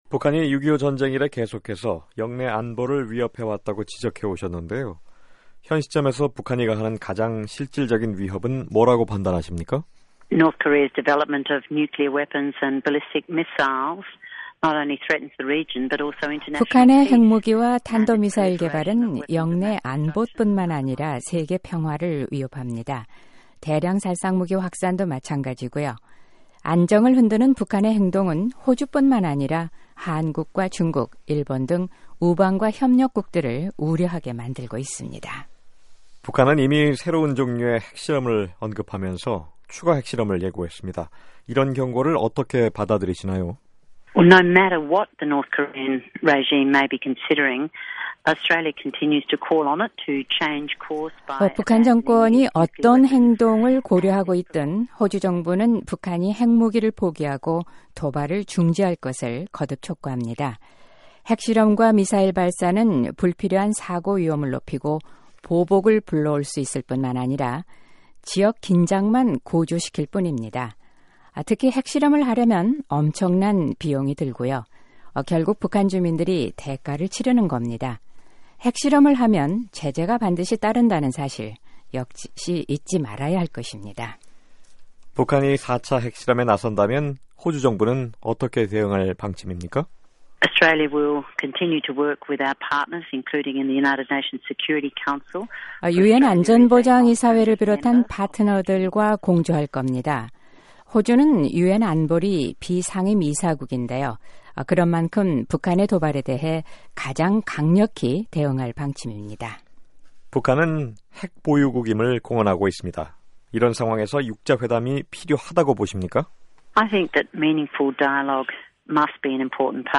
줄리 비숍 호주 외무장관이 VOA와의 단독 인터뷰에서 북한 문제에 관한 입장을 밝혔습니다.